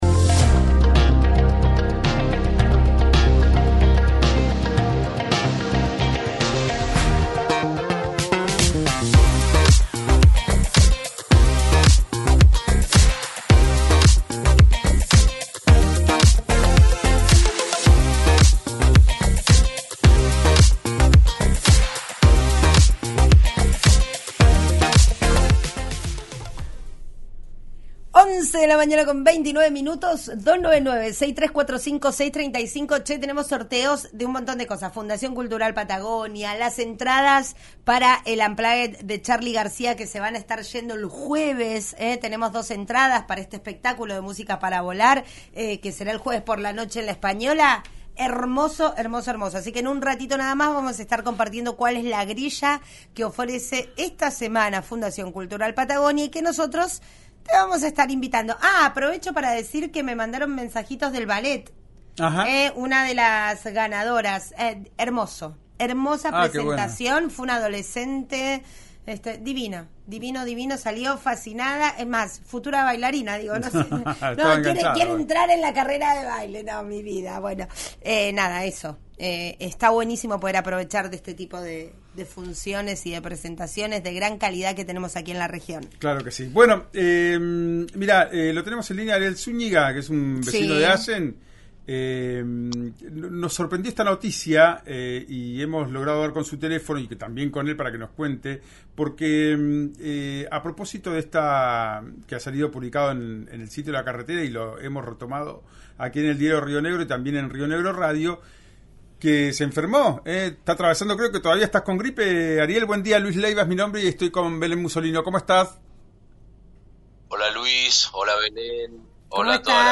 Entre tos y voz ronca contó su aventura: el sábado levantó mucha fiebre y se fue a atender a la guardia del Hospital de Allen.